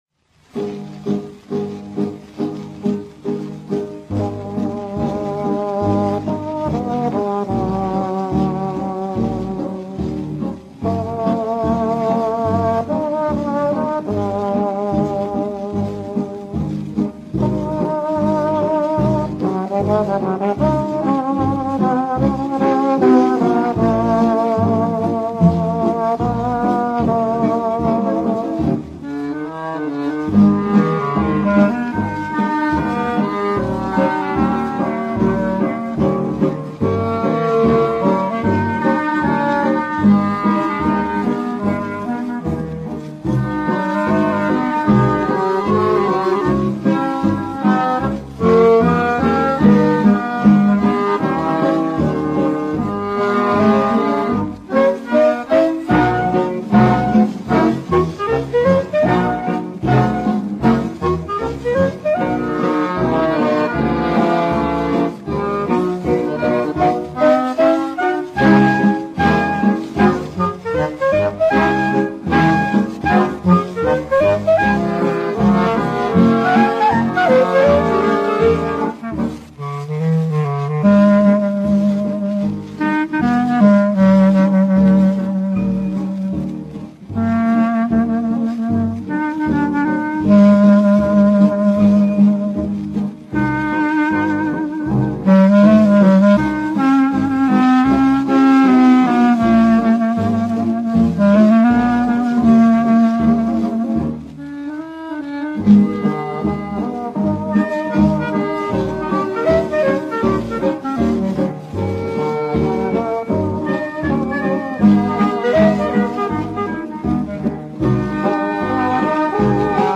И еще более раннее исполнение (78 об. 1939г.)